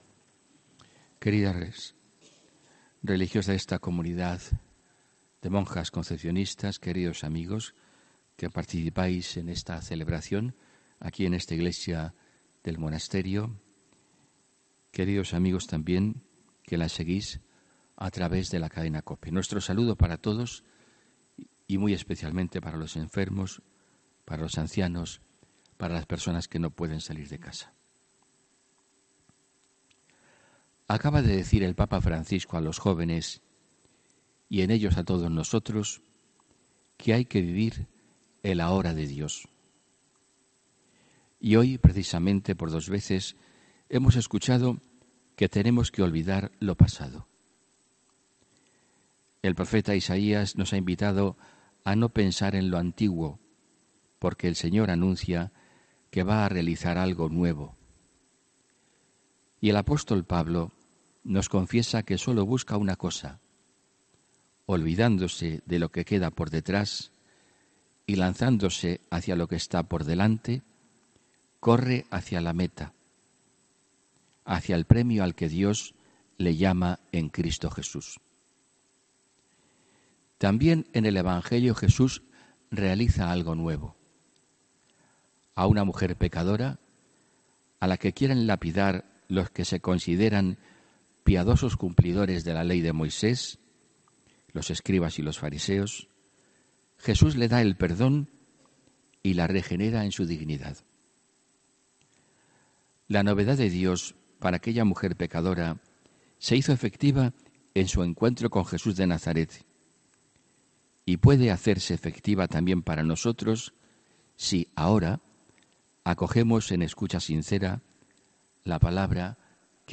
HOMILÍA 7 ABIRL 2019